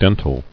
[den·til]